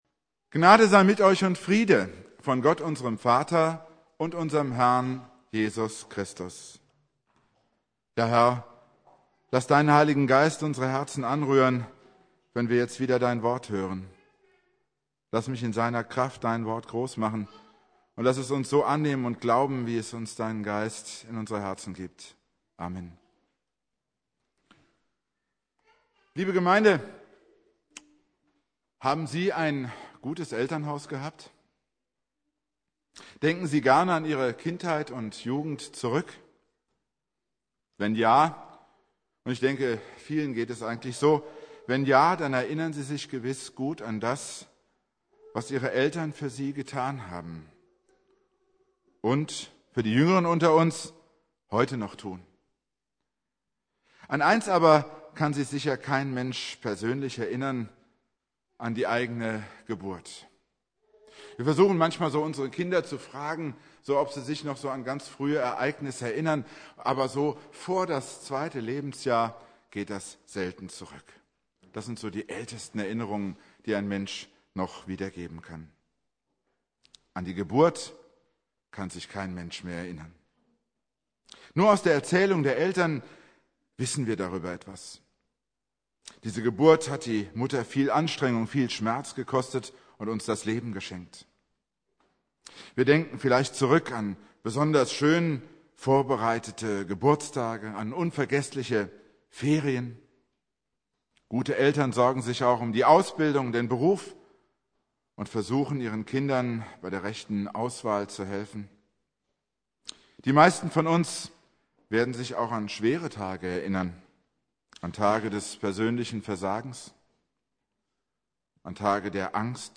Pfingstmontag